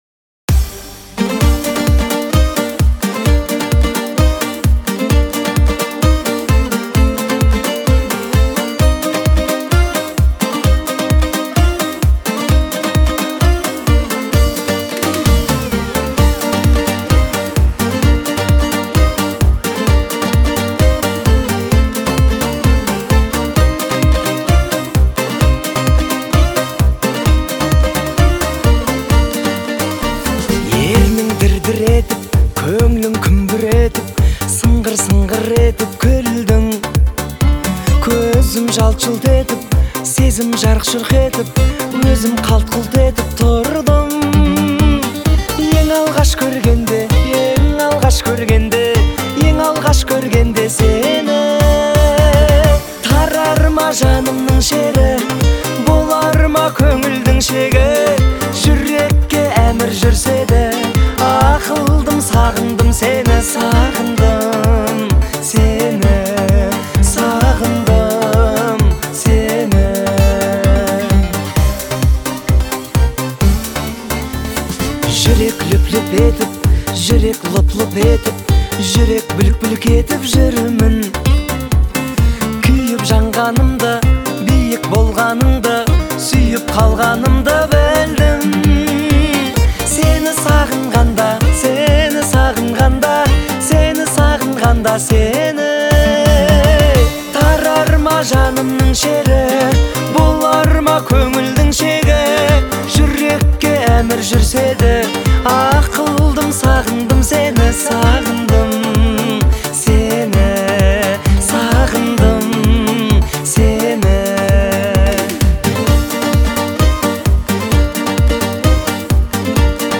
трогательная баллада